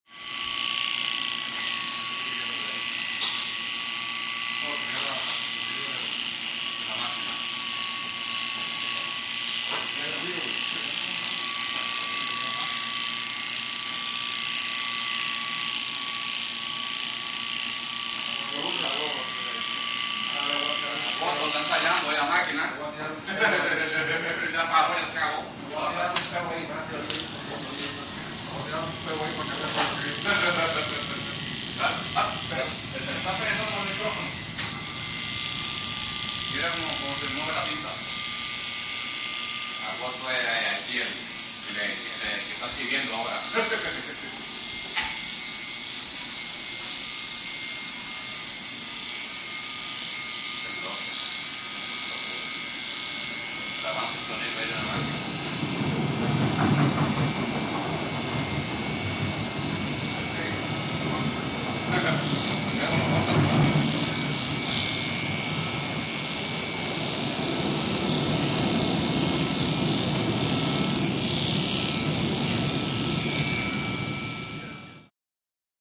Barbershop | Sneak On The Lot
CROWD STORES AND HALLS BARBERSHOP: Electric clippers going close, talking, subway rumble.